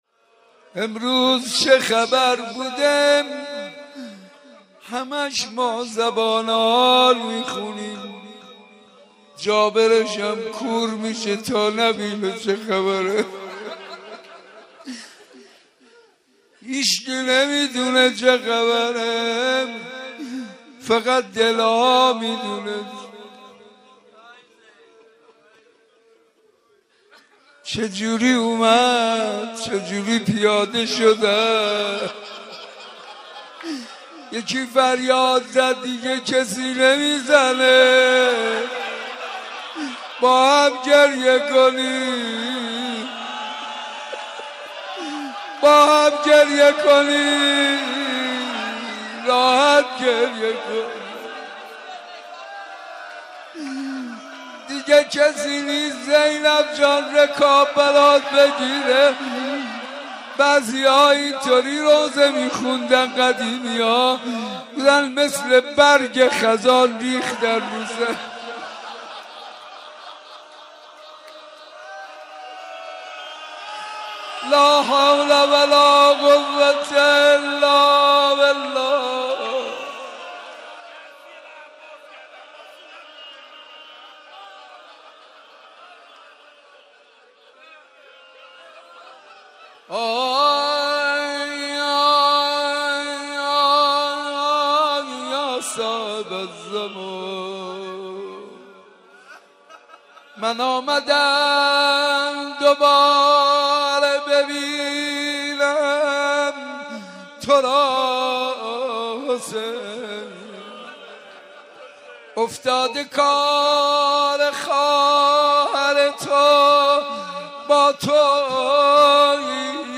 روضه اربعین